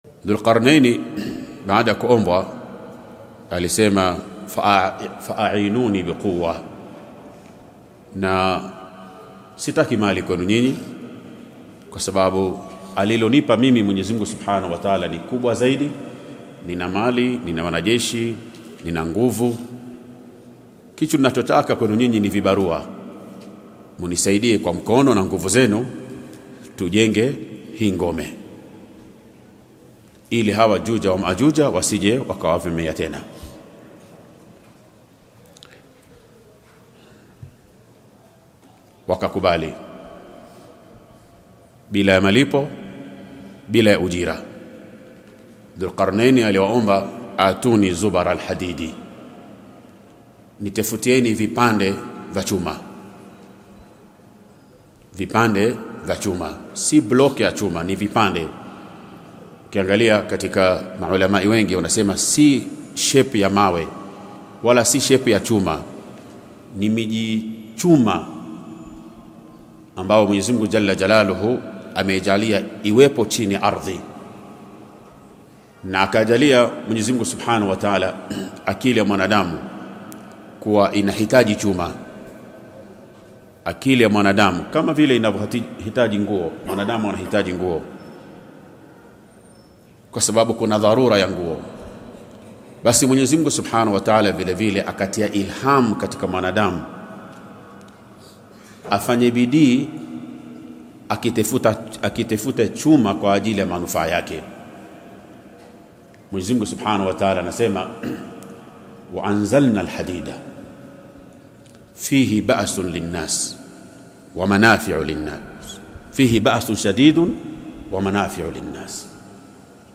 Khutbah